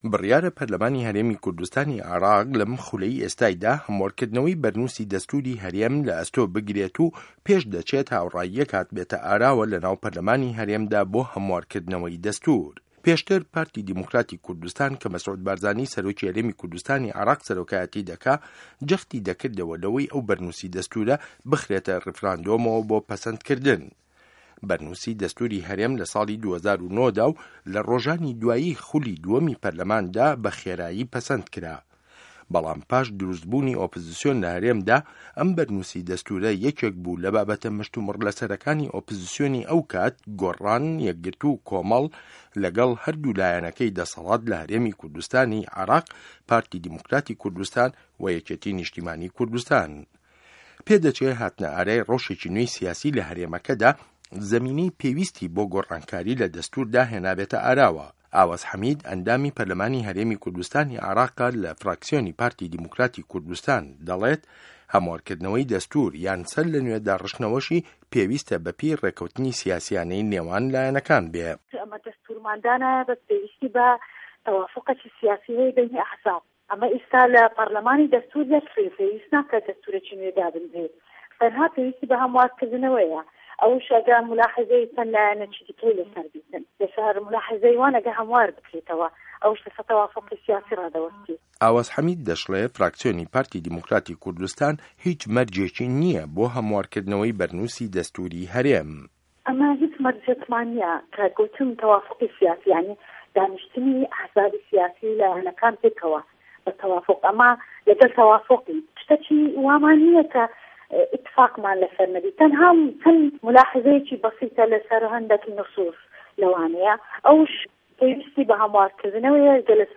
ڕاپۆرتی ده‌ستوری هه‌رێمی کوردستانی عێراق له‌به‌رده‌م ڕیفۆرمدا